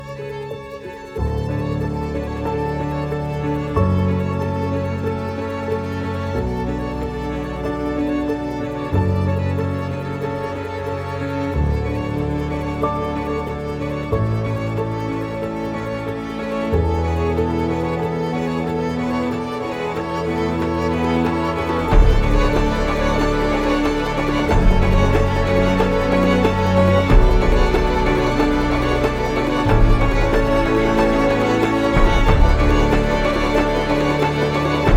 Жанр: Классика
Classical Crossover